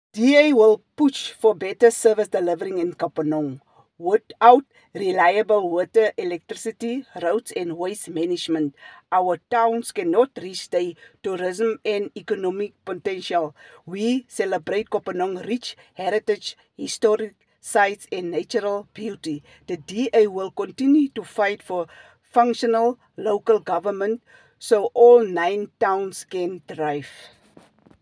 Afrikaans soundbites by Cllr Hessie Shebe and